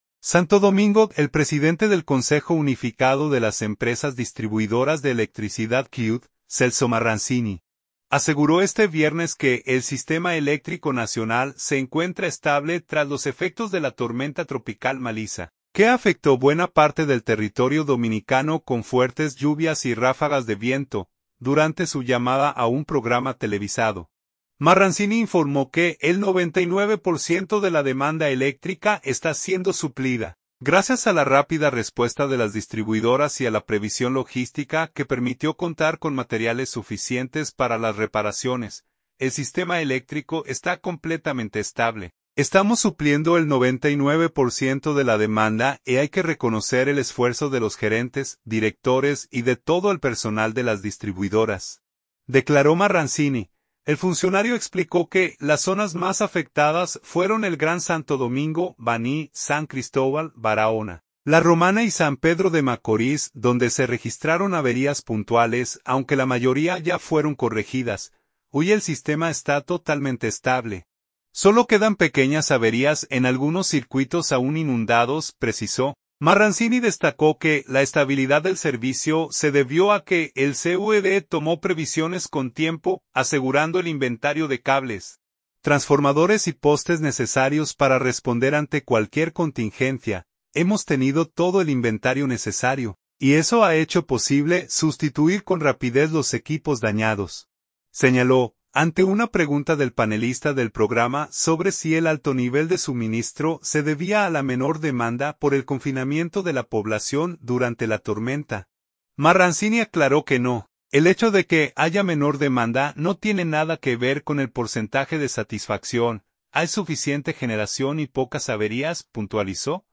Durante su llamada a un programa televisado, Marranzini informó que el 99 % de la demanda eléctrica está siendo suplida, gracias a la rápida respuesta de las distribuidoras y a la previsión logística que permitió contar con materiales suficientes para las reparaciones.